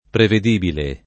vai all'elenco alfabetico delle voci ingrandisci il carattere 100% rimpicciolisci il carattere stampa invia tramite posta elettronica codividi su Facebook prevedibile [ preved & bile ] (raro previsibile [ previ @& bile ]) agg.